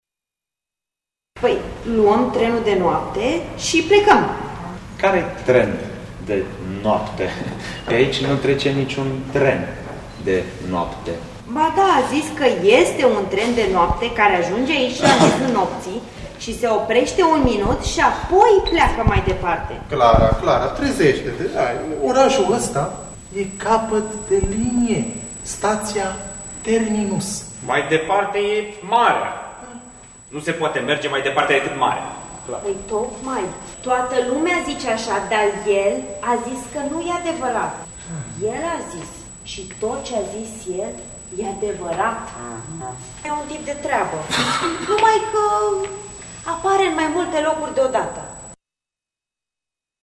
Prezentat de Compania de teatru independent “Portabil”, spectacolul de la Tg Mureş a “rotunjit” lansarea volumului: “Personajul virtual sau calea către al V-lea punct cardinal la Matei Vişniec”.